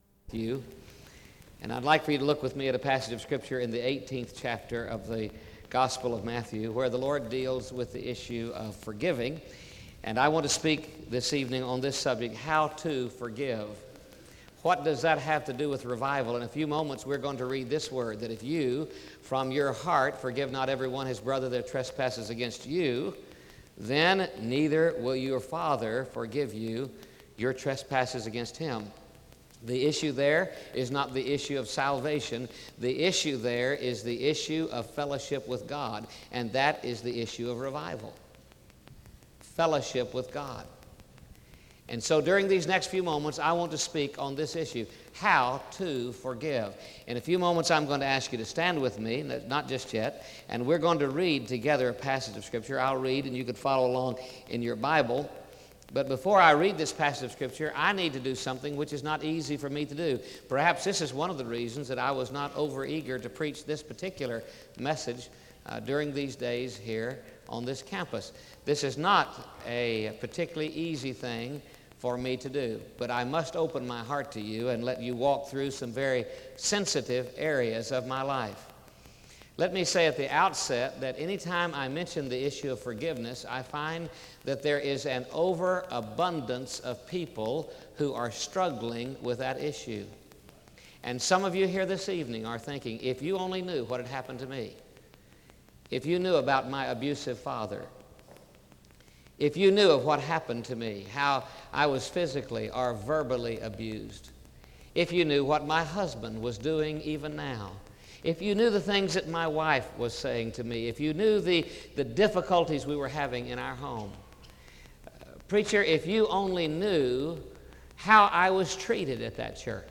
SEBTS Revival
SEBTS Chapel and Special Event Recordings SEBTS Chapel and Special Event Recordings